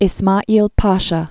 (ĭs-mäēl päshə) 1830-1895.